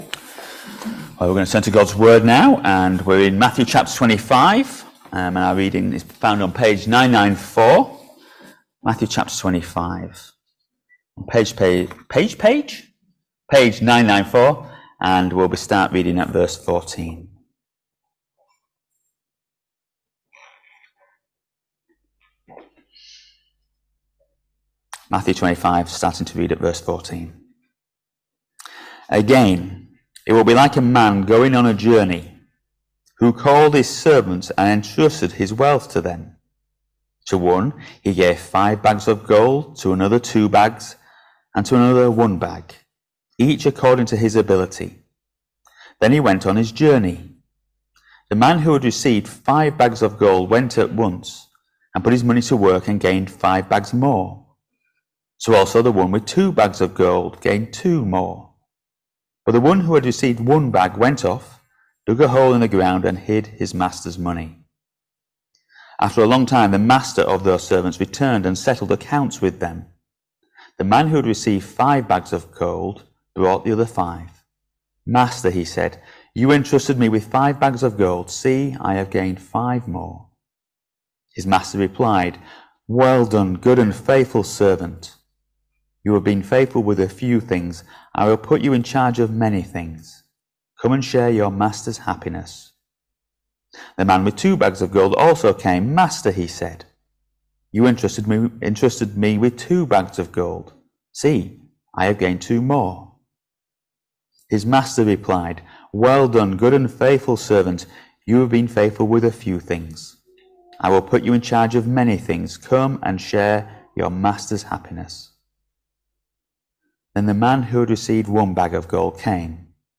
Matthew 25vs14-30 Service Type: Sunday Morning Service Topics